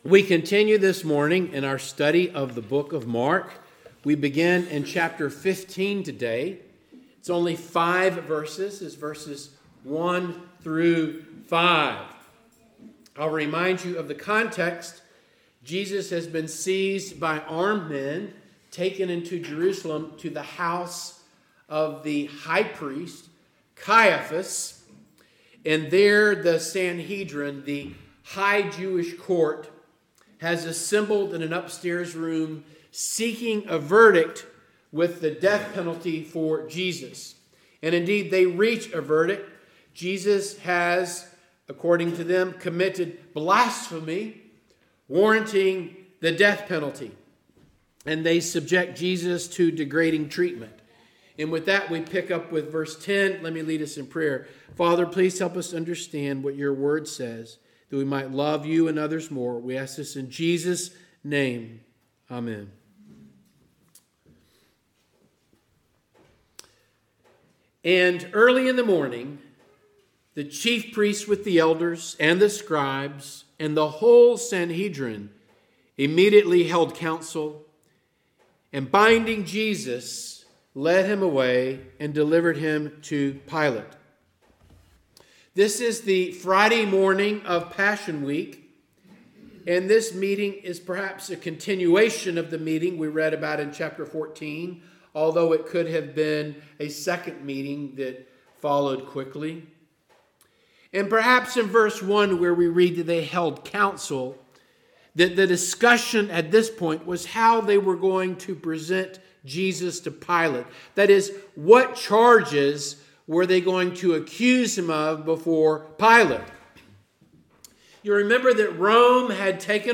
Mark Passage: Mark 15:1-5 Service Type: Morning Service Download Files Bulletin « “I Do Not Know This Man” “They Cried Out